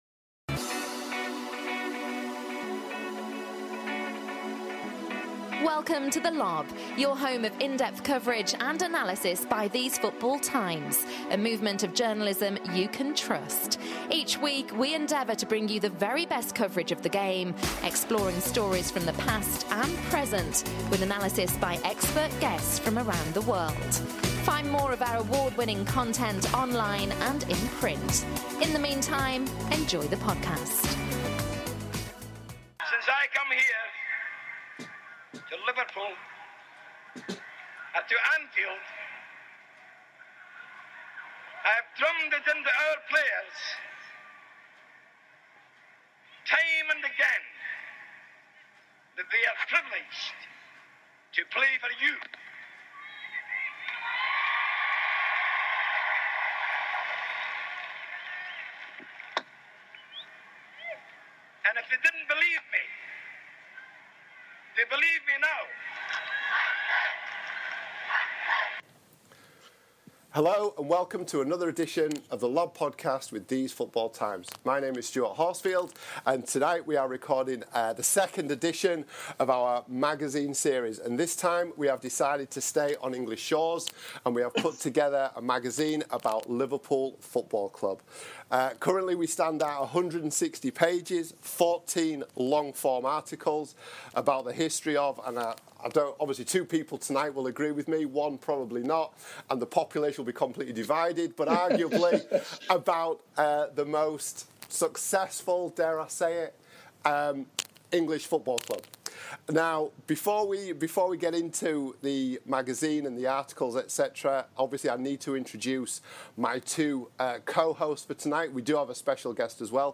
The Liverpool magazine: exclusive audio from our Jamie Carragher interview as we discuss the club